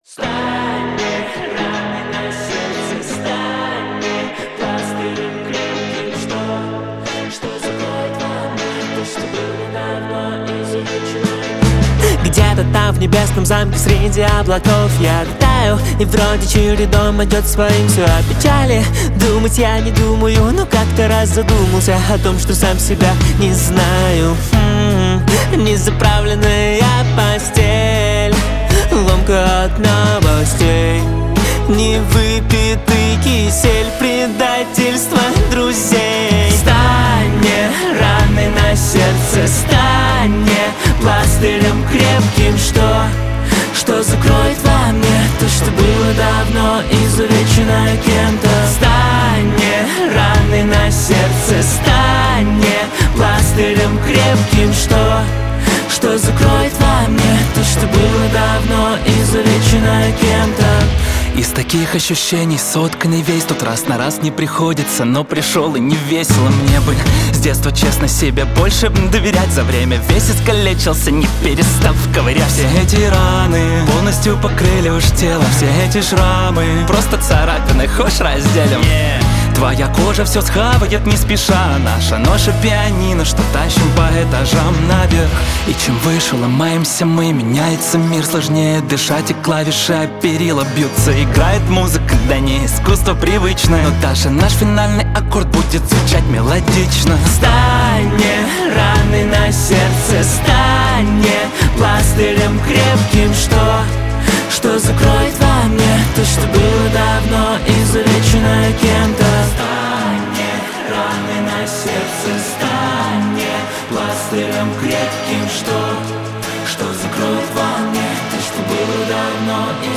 Рэп, Новинки